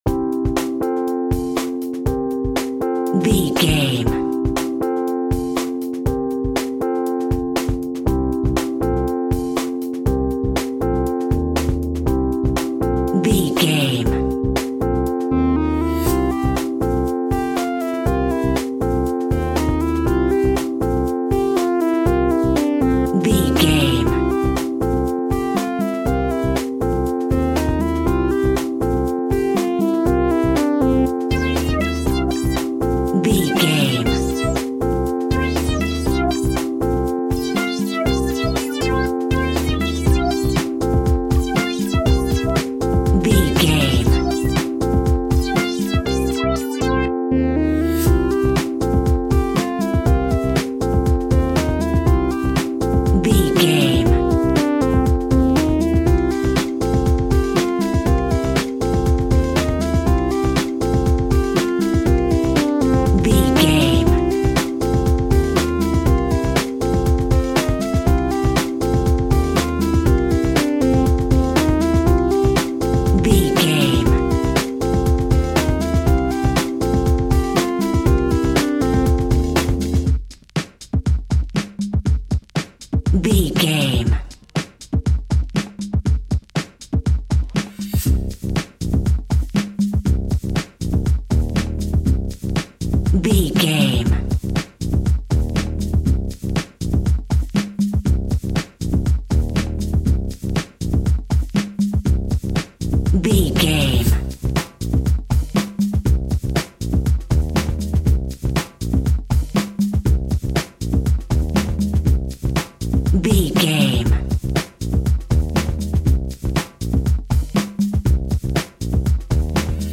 Ionian/Major
DOES THIS CLIP CONTAINS LYRICS OR HUMAN VOICE?
Fast
funky house
disco funk
soul jazz
drums
bass guitar
electric guitar
piano
hammond organ